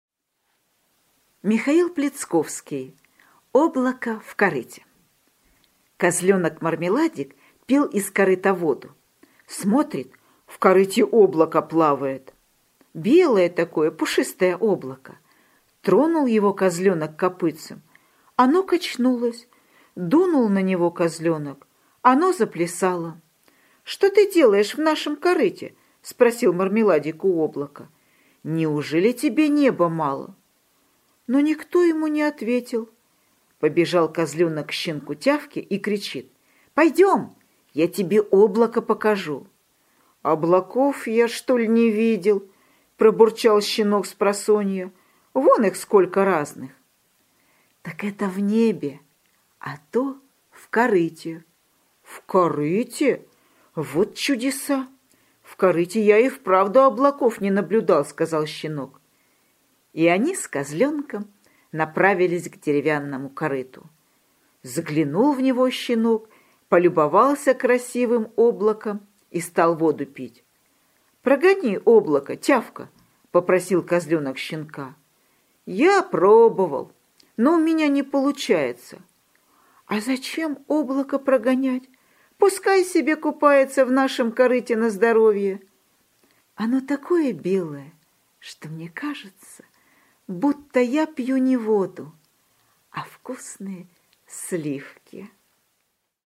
Аудиосказка «Облако в корыте»